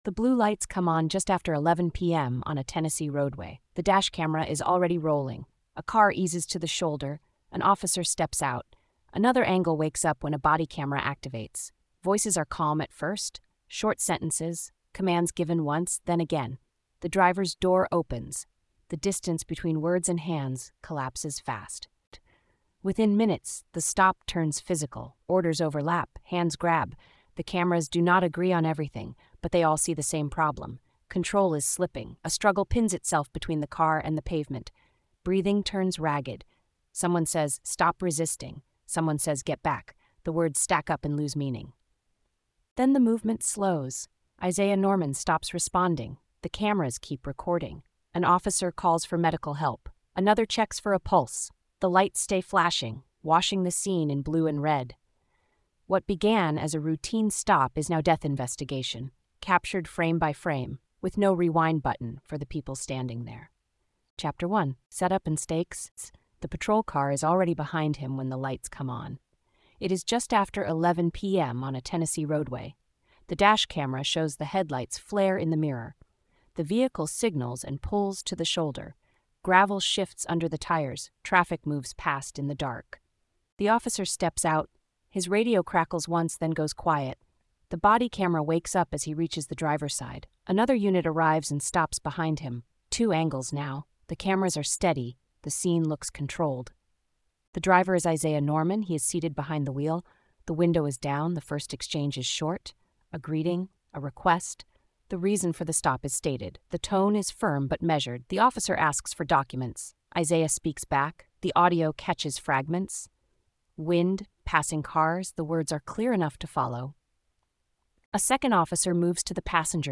Using synchronized dash camera, body camera, and surveillance footage, the story reconstructs the encounter second by second, separating what is visible and provable from what remains disputed. The narrative follows the escalation from routine stop to physical restraint, the medical findings that explain how life was lost, and the investigative process that shaped the legal outcome. Told in a neutral but gritty forensic voice, the series focuses on evidence, procedure, and consequence rather than speculation or sensationalism.